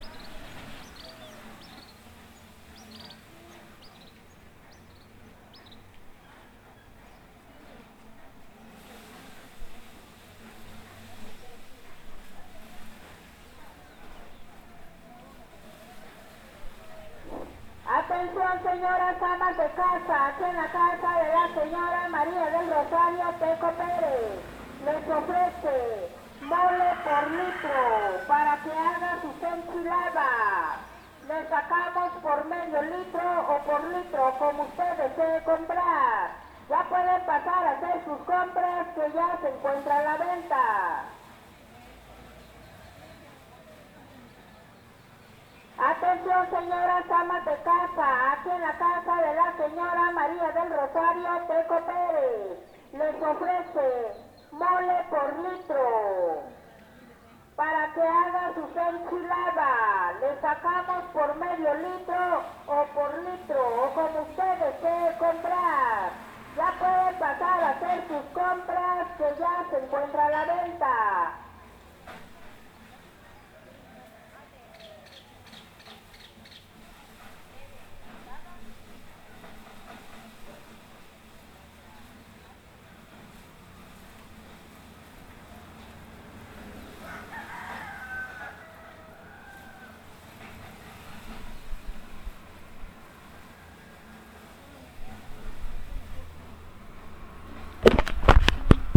Anuncio: Mole
Palo que habla o bocina de anuncios públicos en el pueblo, la mención tiene un costo aproximado de $30.00 pesos.
Grabación realizada el 30 de julio de 2021 a las 9:00 a.m. en el municipio de Suchiapa, Chiapas; México.